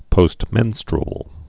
(pōst-mĕnstr-əl)